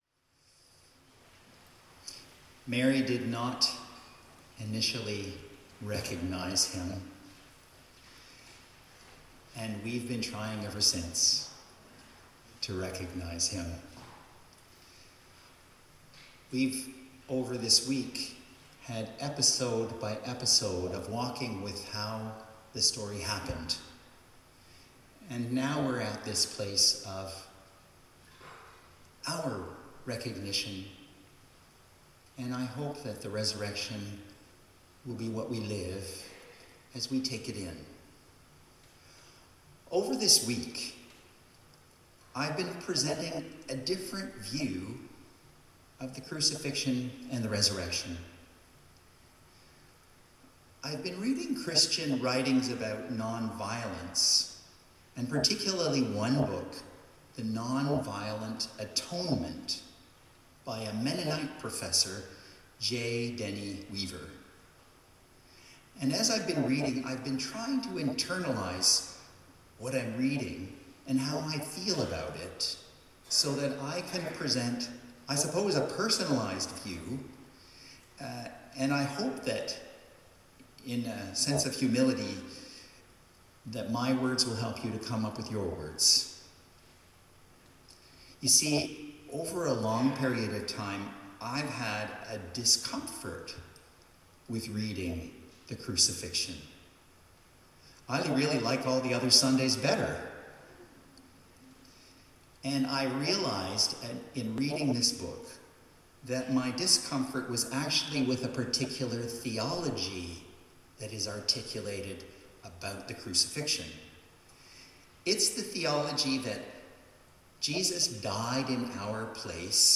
Sermons | St John the Evangelist